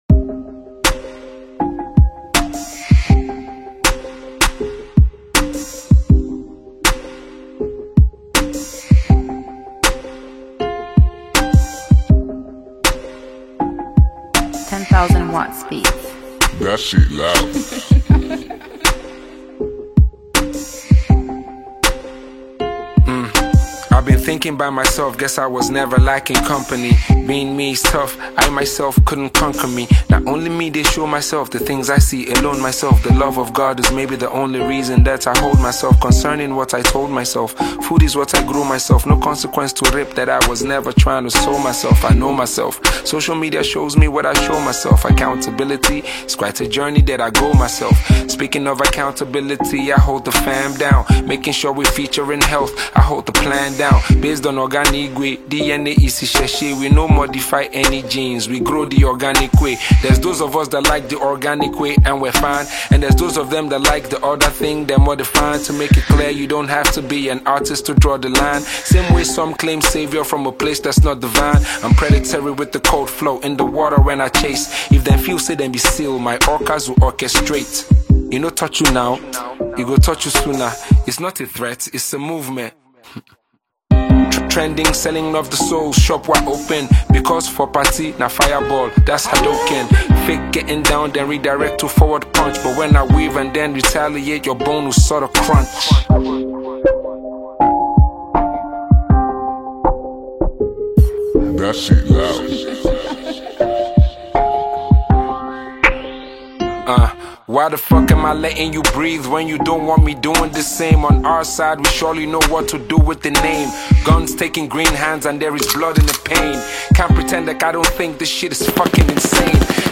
Nigerian rap
gritty hip-hop beat with flawless flow